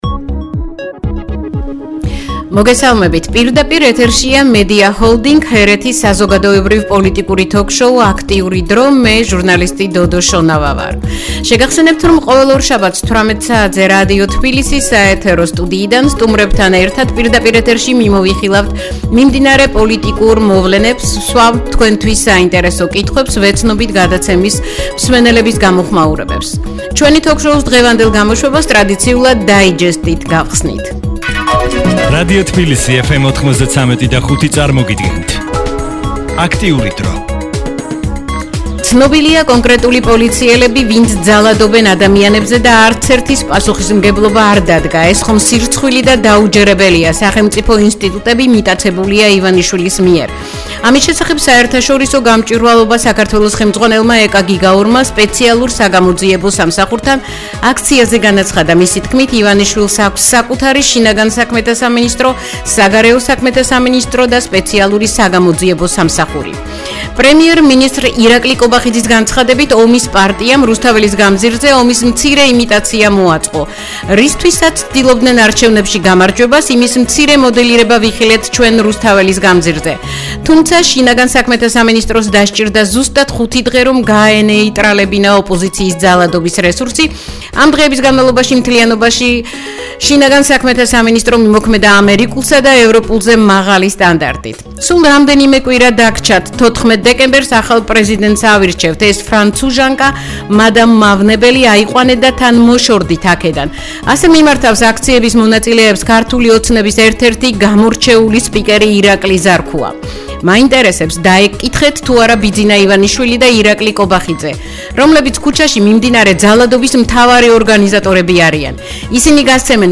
პირველი სტუმარი: ჟურნალისტი